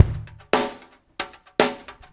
SPL BR DRM-L.wav